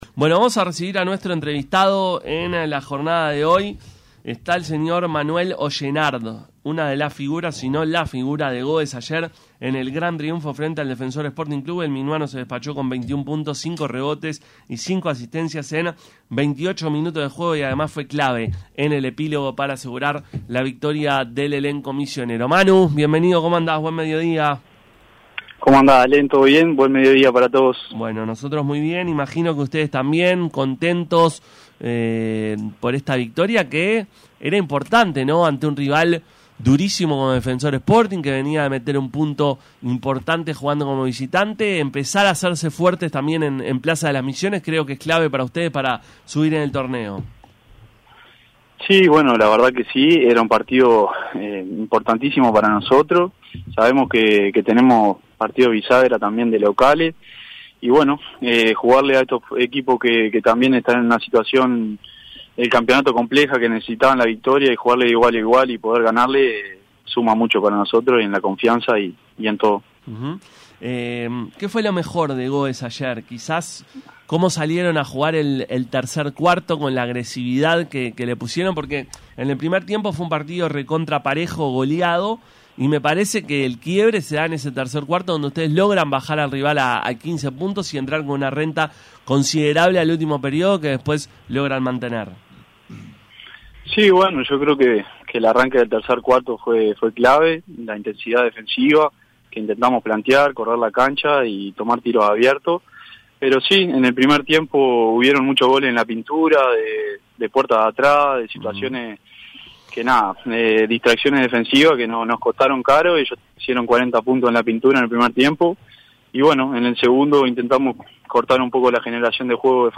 habló con Pica La Naranja luego del triunfo ante Defensor Sporting.